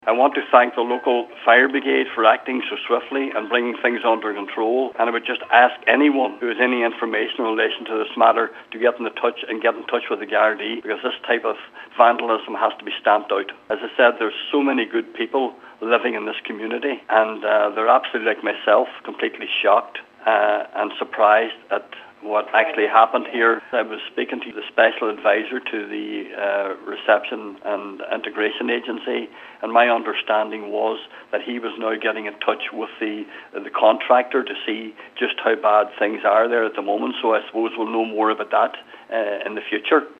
Local Councillor Martin Farren says the community of Moville are in shock over what’s happened: